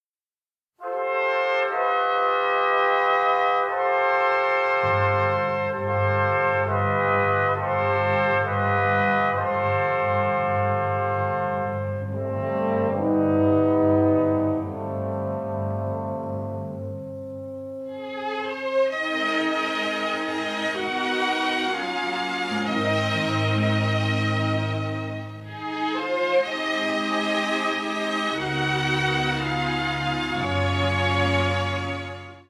a lyrical main theme that bookends the film